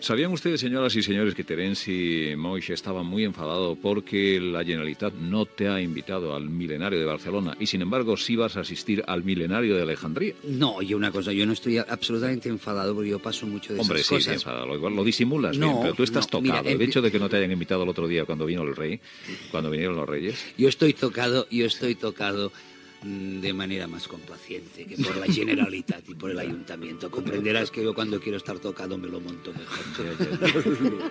Fragment d'una entrevista a l'escriptor Terenci Moix
Info-entreteniment